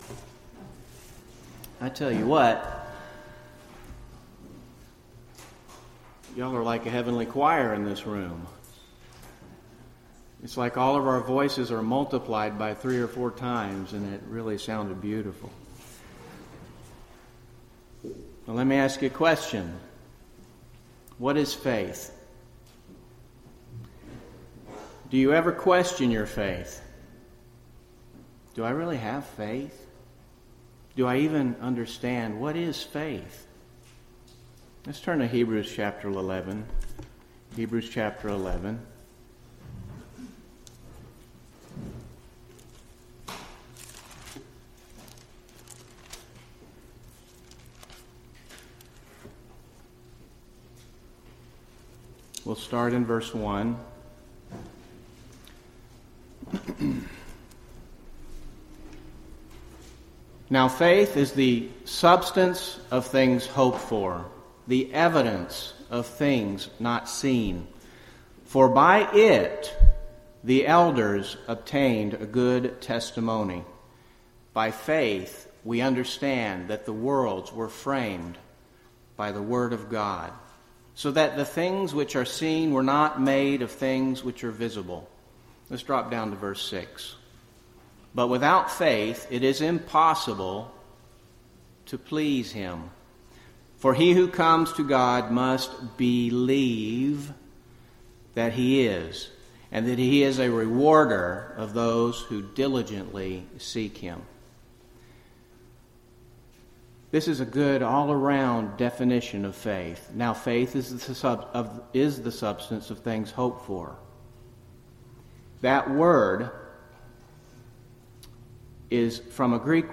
UCG Sermon Christian faith Believing is not enough Notes PRESENTER'S NOTES Do you ever question your faith?